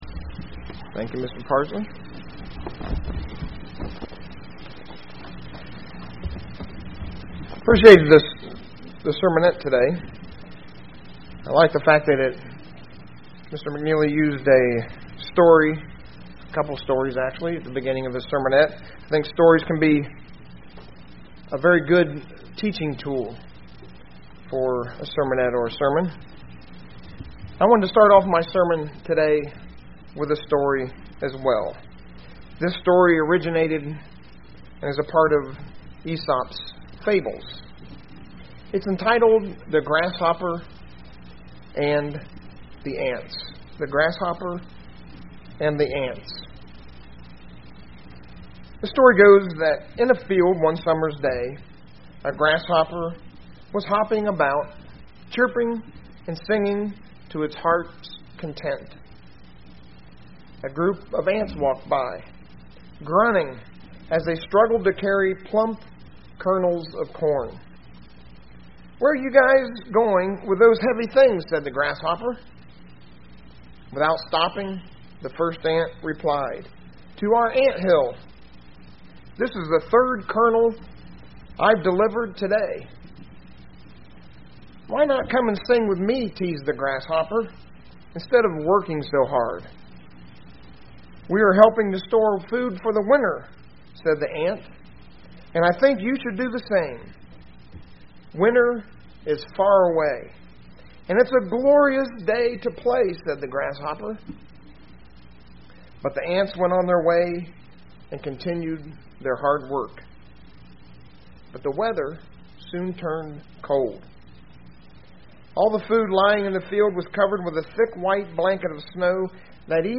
UCG Sermon Notes Notes: What happens when we don't live a life of self-discipline.